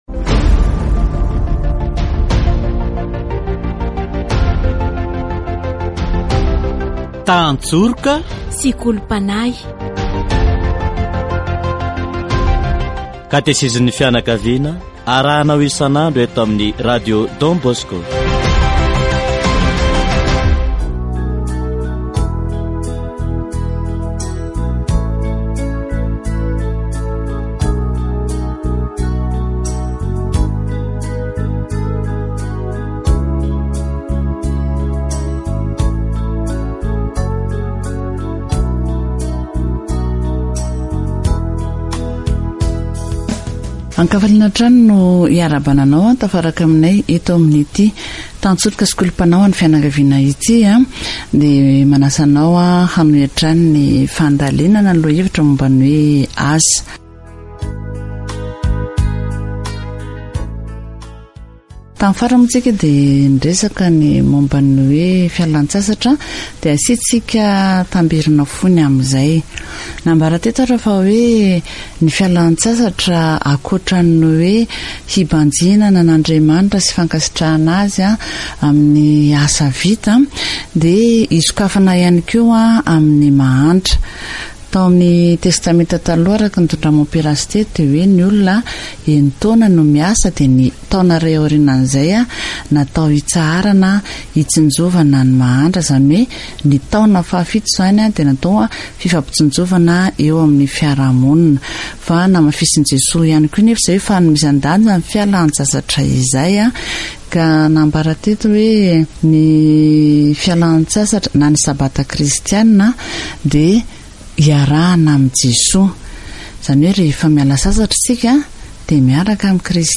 Catechesis on work